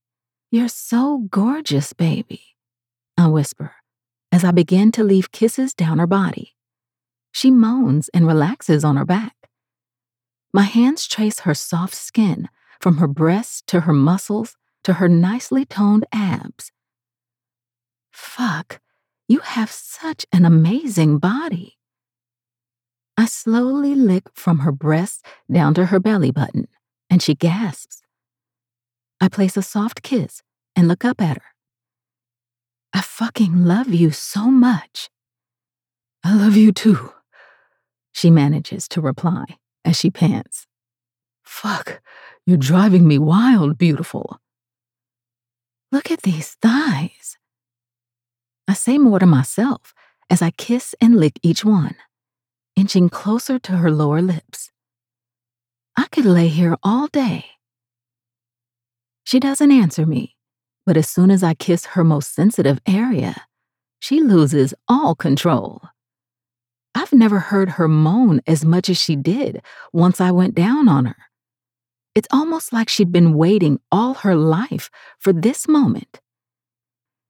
F/F Explicit 1st POV Sapphic Romance
My voice is often described as warm and feminine, with a depth that brings a unique richness to every story I narrate.
I believe in delivering high-quality audio, and to achieve this, I use top-notch home studio equipment, including a Double-Walled VocalBooth, Neumann TLM 102, RØDE NT1 5th Gen, Sennheiser 416, and a Focusrite Scarlett 2i2 interface.